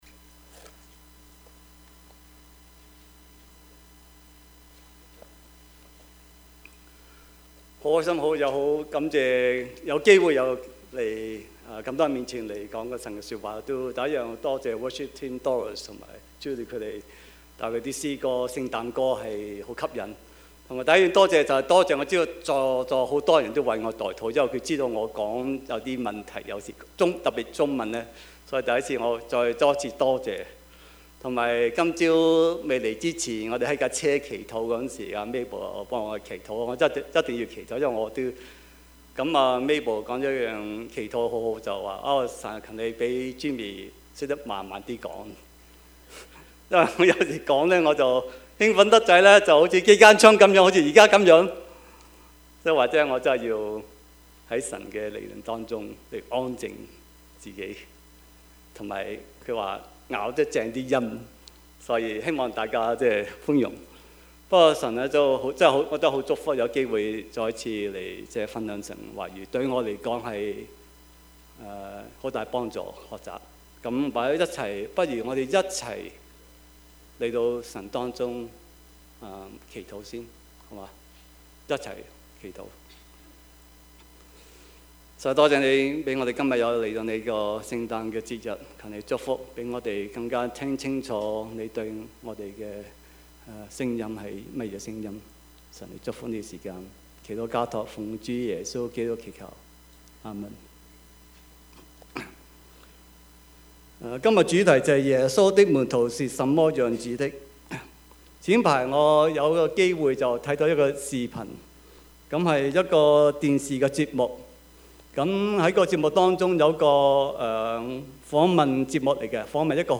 Service Type: 主日崇拜
Topics: 主日證道 « 尊主為大 冷暖人間 »